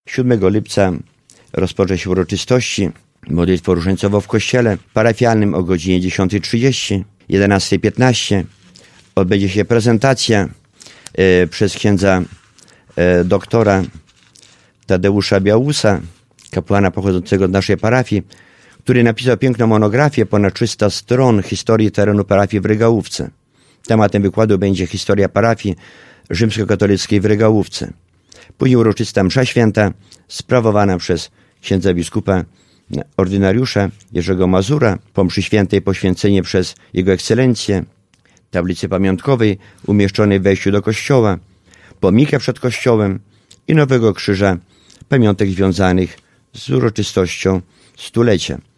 Uroczystości odbędą się 7 lipca. Wszystko rozpocznie się o godzinie 10.30. Będzie modlitwa różańcowa, prezentacja multimedialna o historii parafii i msza święta. O szczegółach mówił we wtorek (11.06.19) w Radiu 5